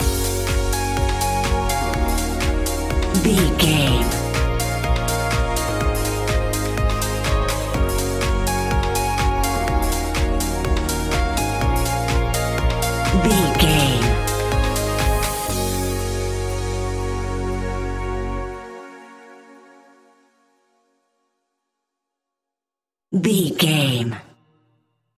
Aeolian/Minor
B♭
groovy
hypnotic
uplifting
drum machine
synthesiser
house
electro house
synth leads
synth bass